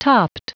Prononciation du mot topped en anglais (fichier audio)
topped.wav